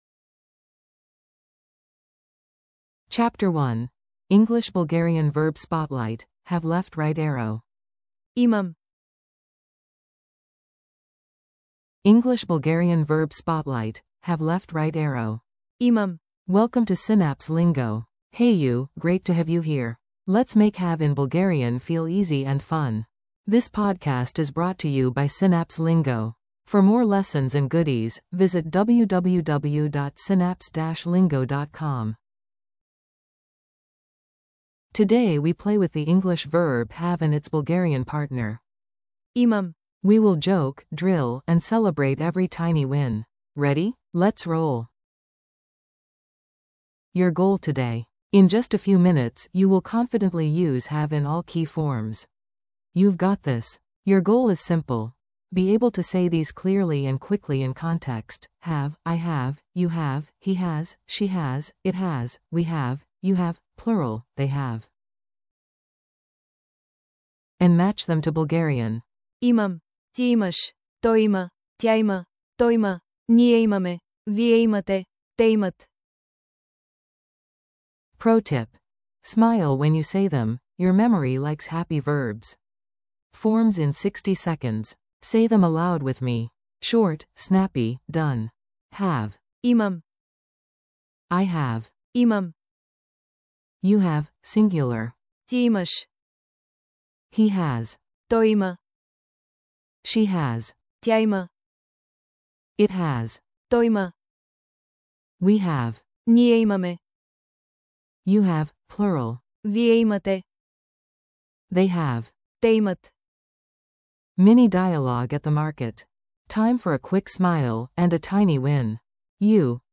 Audio for repeating & practicing